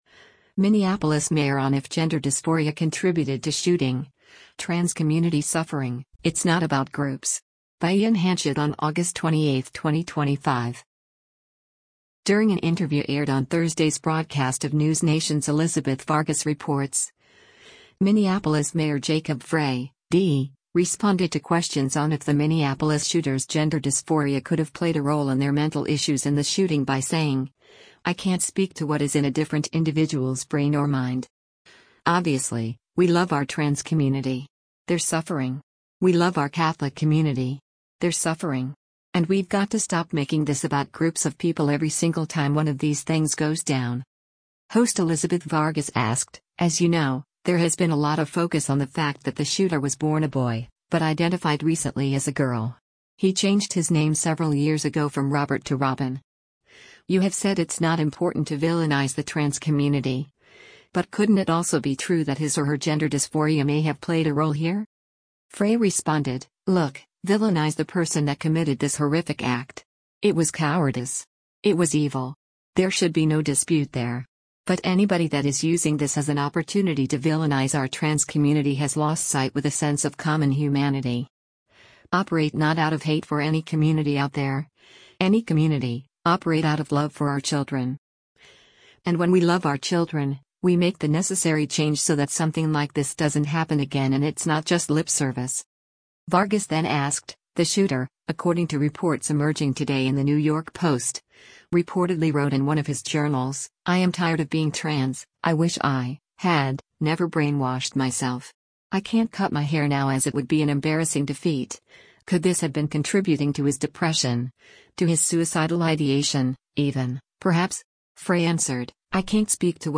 During an interview aired on Thursday’s broadcast of NewsNation’s “Elizabeth Vargas Reports,” Minneapolis Mayor Jacob Frey (D) responded to questions on if the Minneapolis shooter’s gender dysphoria could have played a role in their mental issues and the shooting by saying, “I can’t speak to what is in a different individual’s brain or mind.